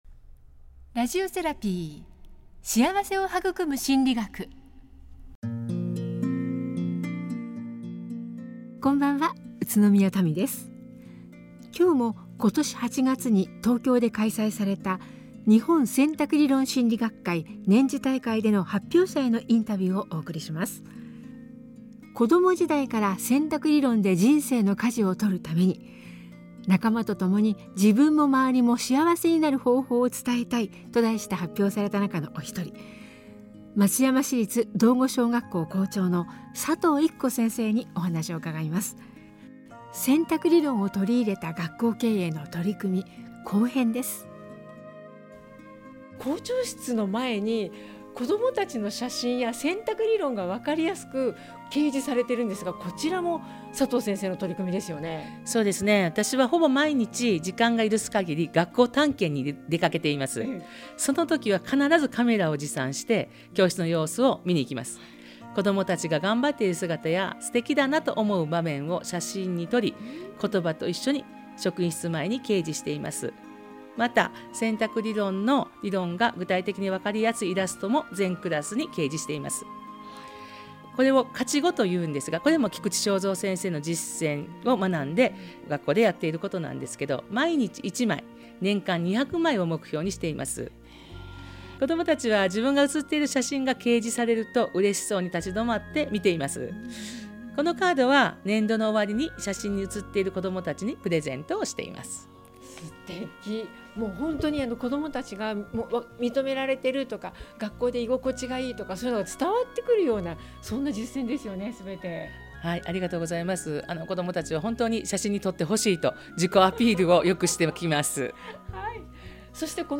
先生方にもインタビューしました。